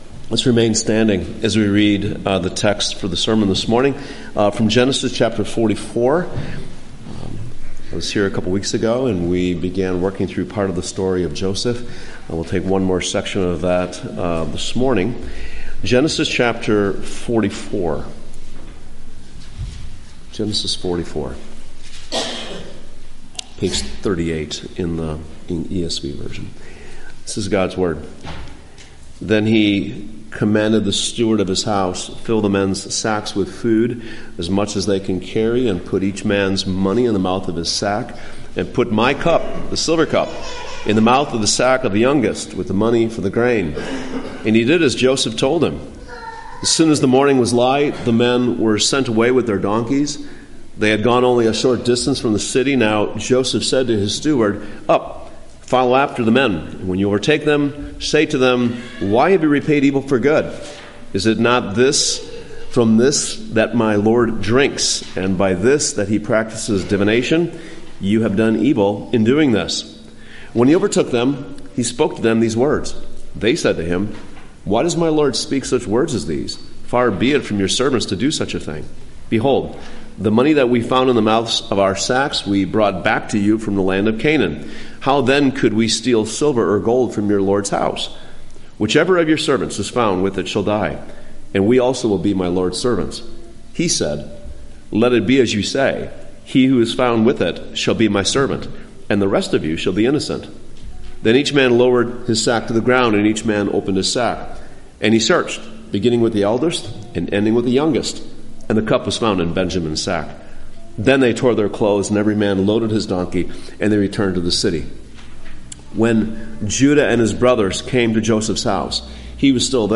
Morning Sermon
Posted in Sermons